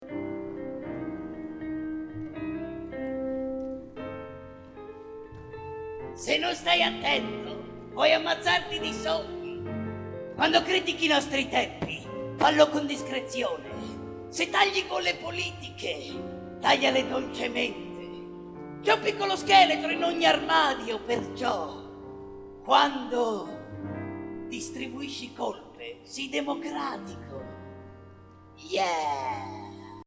GenereJazz